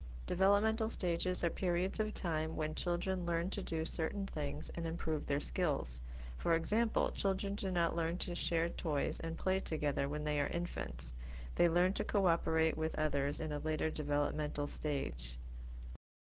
When you see the speaker speaker after each of the definitions you can hear the pronunciation of the term and what it means.